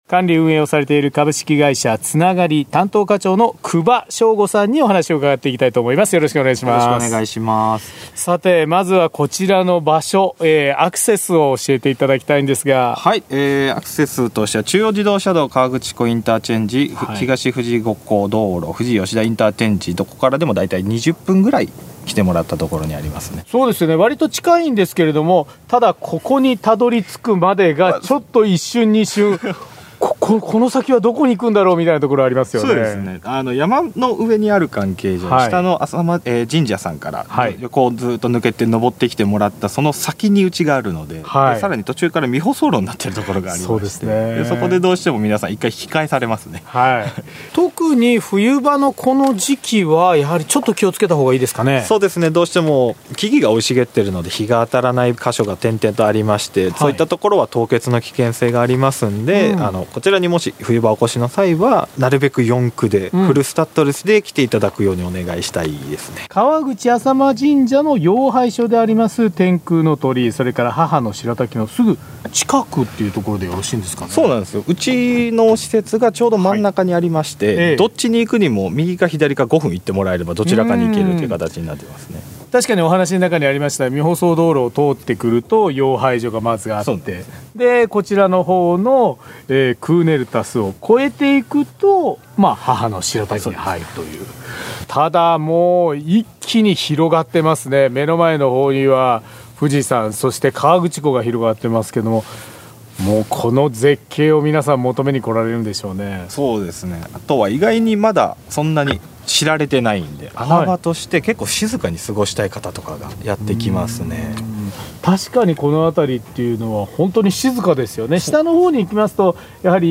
毎週土曜午前11時からの生放送。